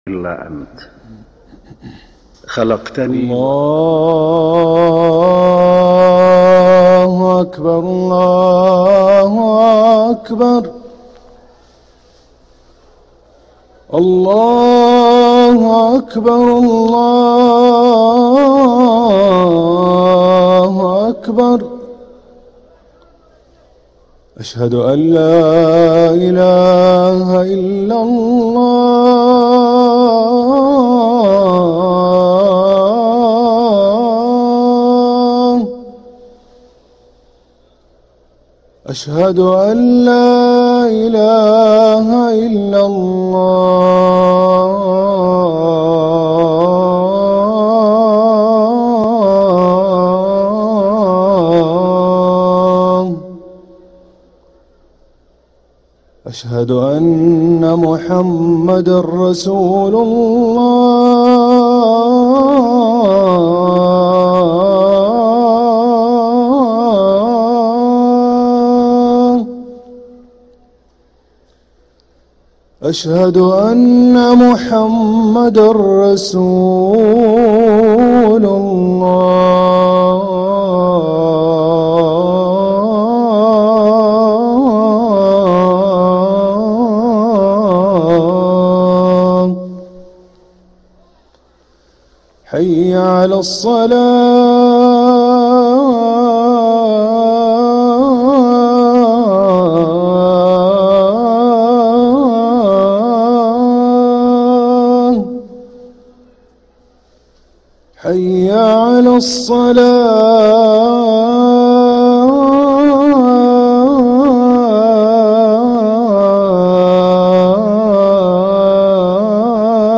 أذان الظهر للمؤذن